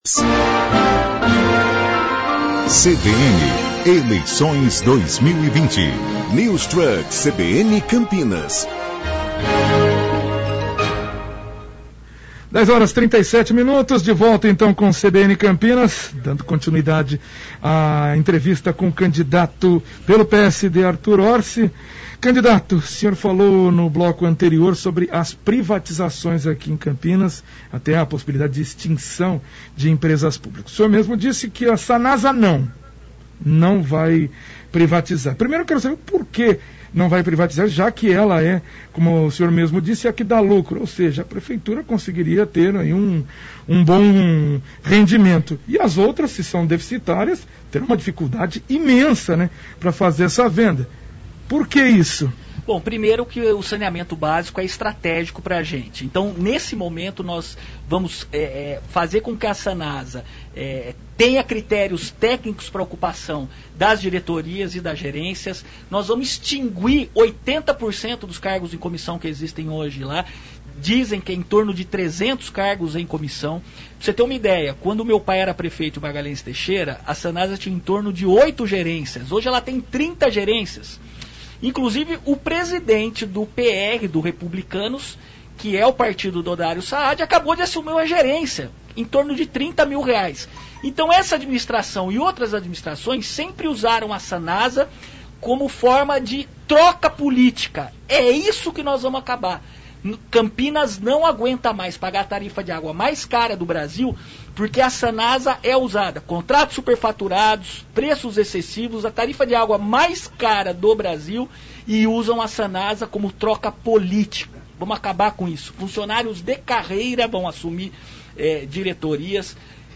Entrevista com o candidato Artur Orsi, do PSD – Parte 02 - CBN Campinas 99,1 FM
Candidato a prefeito de Campinas , Artur Orsi, participa da rodada de entrevistas da CBN Campinas.